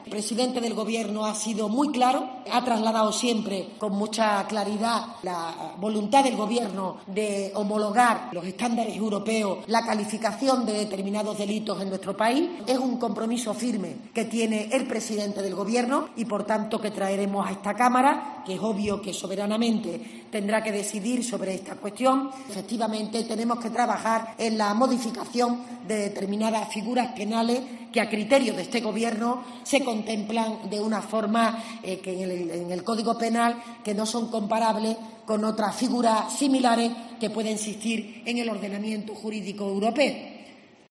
Desde la tribuna del Congreso, y en medio del debate de Presupuestos Generales del Estado, María Jesús Montero ha asegurado que homologar el Código Penal a los estándares europeos es un compromiso de Pedro Sánchez.